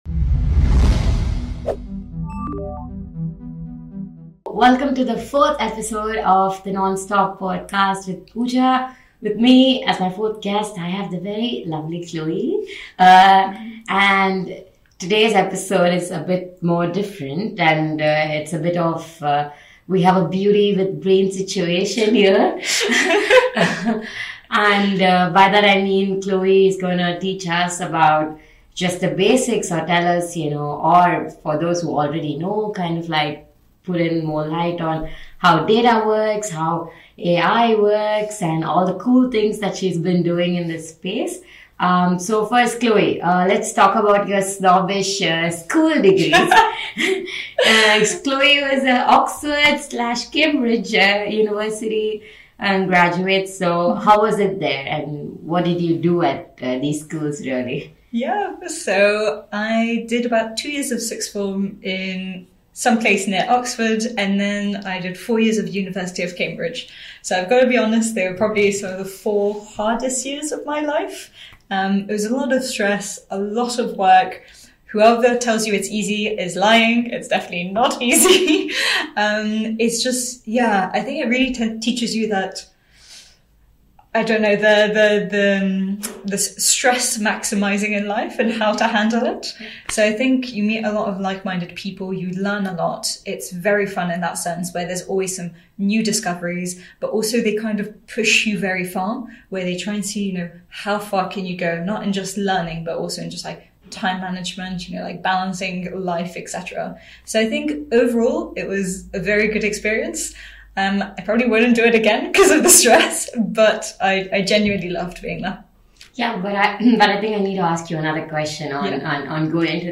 Get ready for a thought-provoking conversation that portrays the power of data and its impact on our lives.